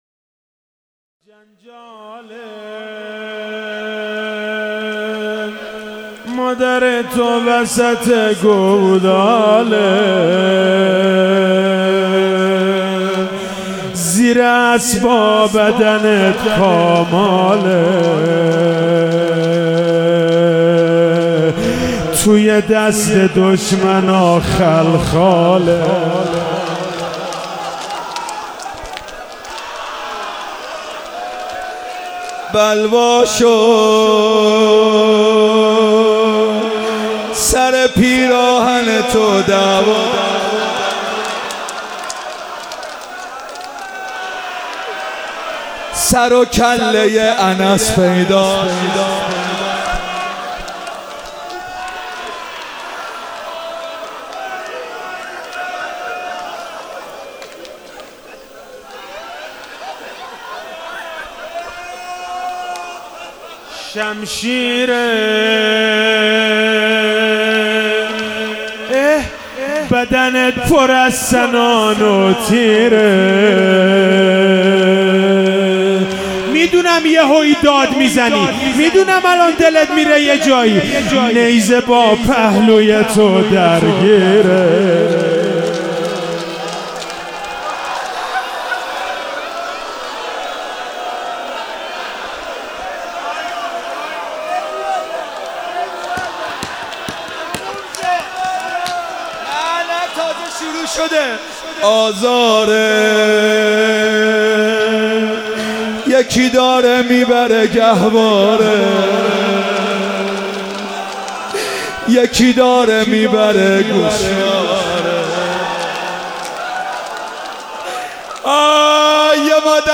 شهادت امام کاظم(ع)98 - روضه - جنجاله مادر تو وسط گوداله